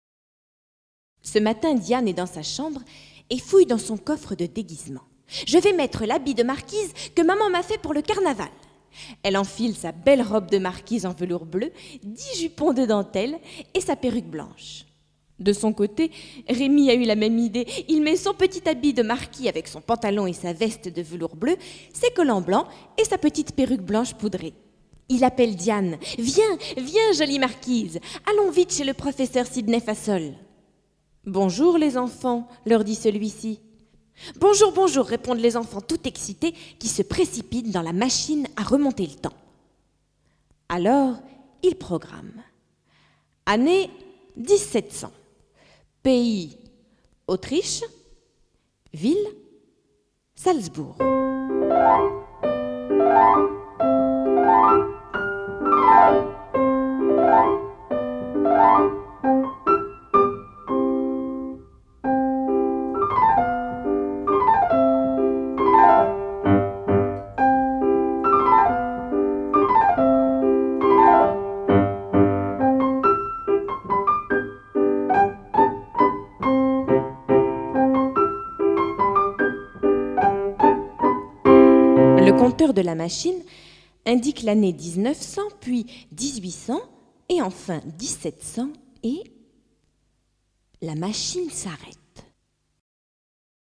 Contes audio des compositeurs de musique à télécharger